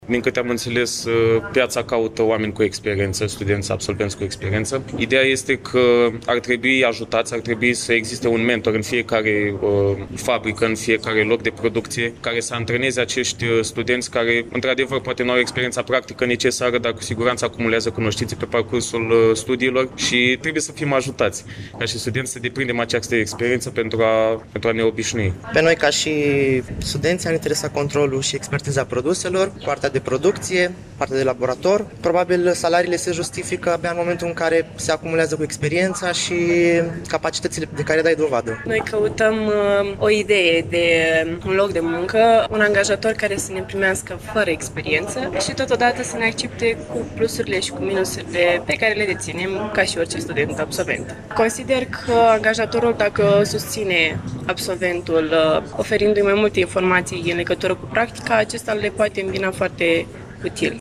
Studenții spun că cel mai mare impediment în ceea ce privește angajarea îl reprezintă practica în domeniul agro-alimentar:
8-apr-ora-13-Vox-studenti.mp3